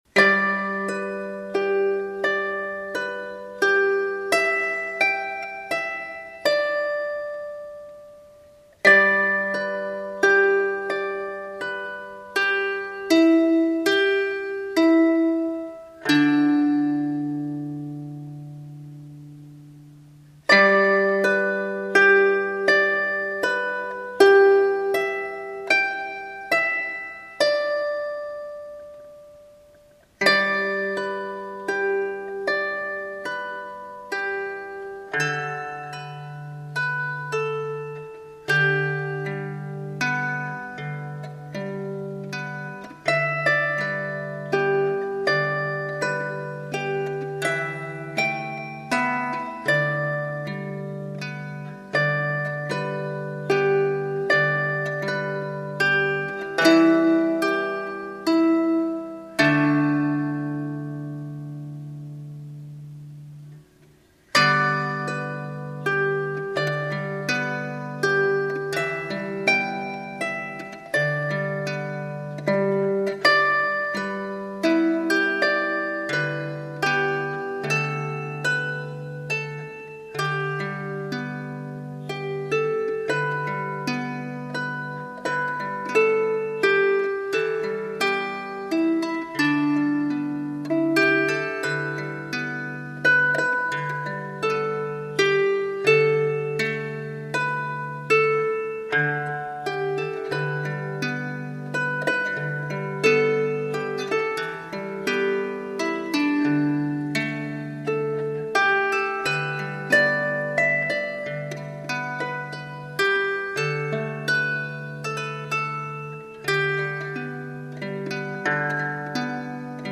This is an original instrumental, played on the harp.